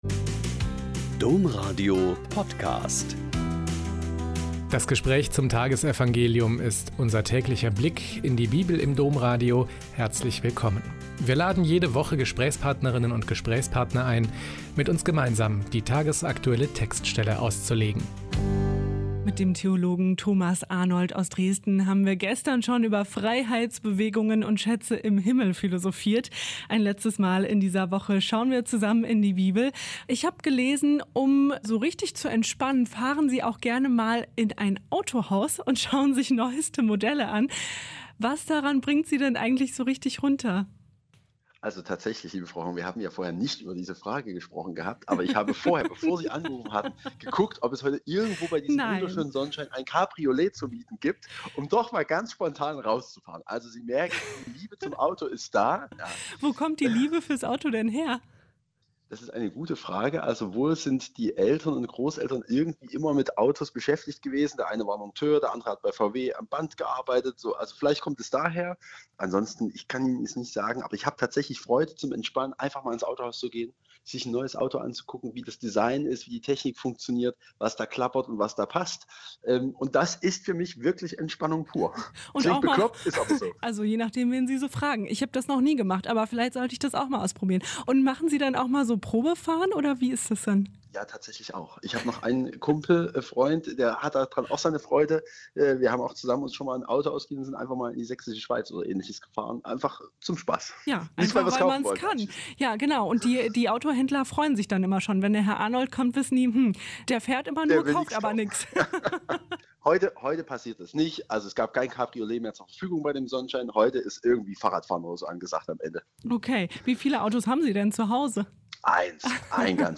Mt 6,24-34 - Gespräch